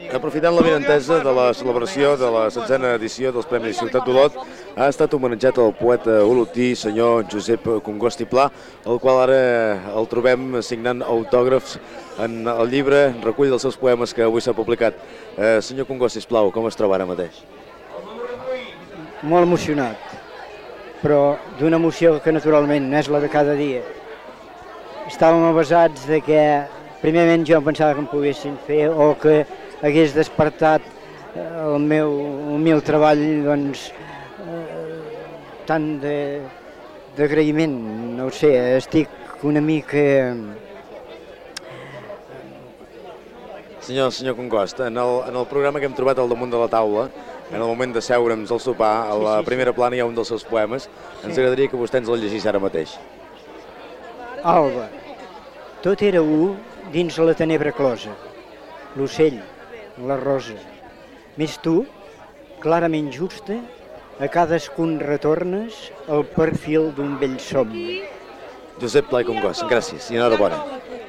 XVI Premis Ciutat d'Olot, celebrats el 24 d'abril del 1982.